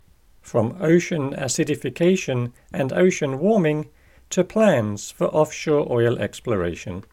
Breaking News English | Dictation | Coral Reef